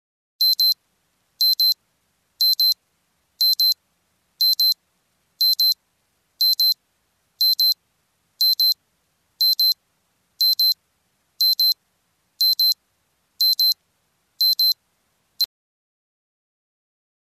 Звуки часов, курантов
Звук Наручные электронный часы, сработал будильник, бип, бип